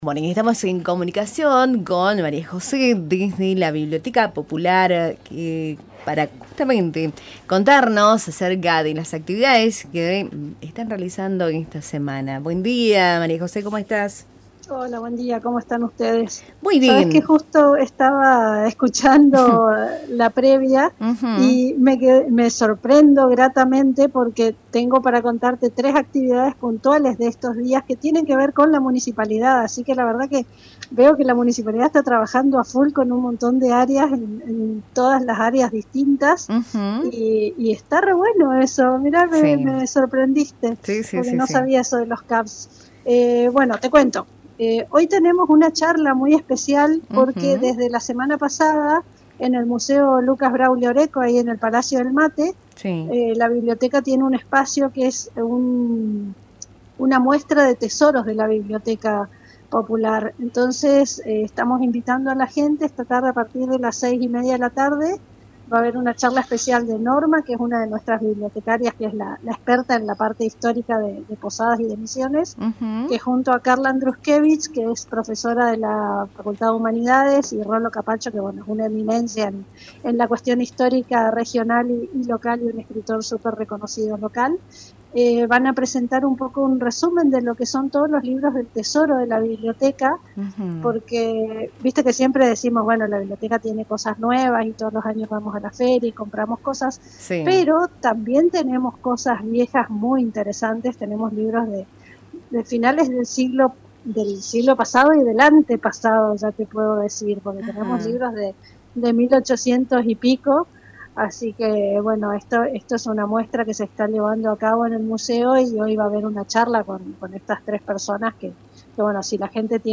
En comunicación con Cultura en Diálogo